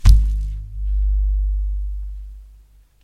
气球低音炮 " 气球低音炮11
描述：Balloon Bass Zoom H2
标签： 贝斯 气球
声道立体声